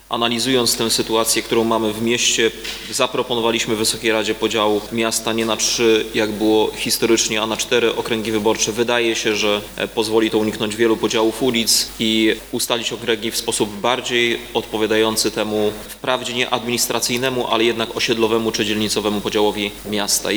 – mówi prezydent Rafał Zając.